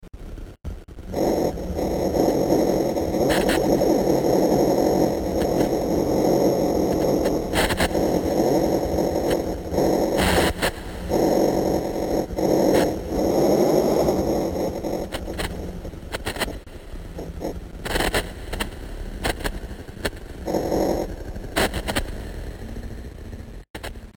دانلود آهنگ رادیو 26 از افکت صوتی اشیاء
جلوه های صوتی
دانلود صدای رادیو 26 از ساعد نیوز با لینک مستقیم و کیفیت بالا